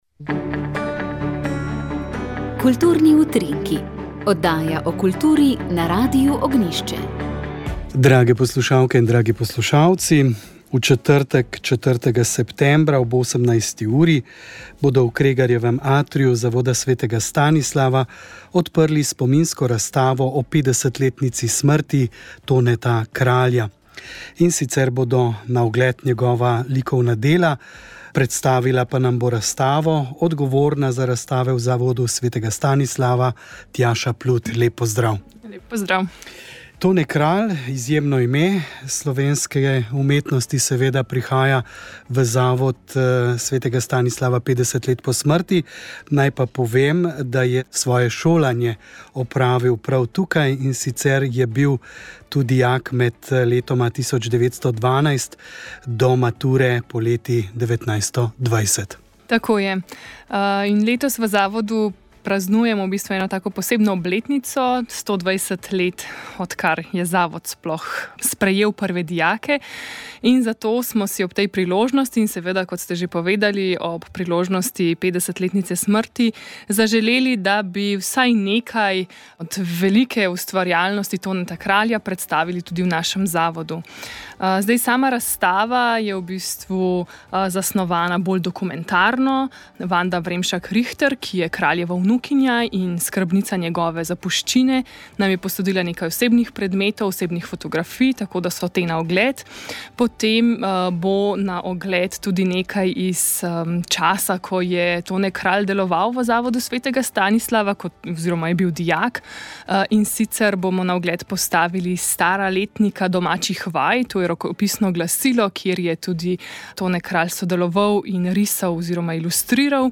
Tokrat smo povezali meteorologijo in astronomijo. Naš gost je bil ljubiteljski meteorolog in astronom